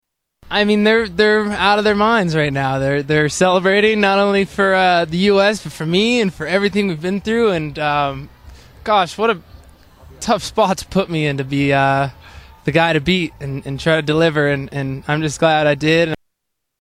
Family celebrating his second gold